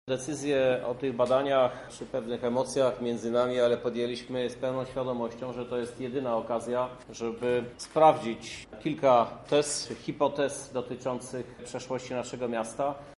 -mówi prezydent miasta Krzysztof Żuk.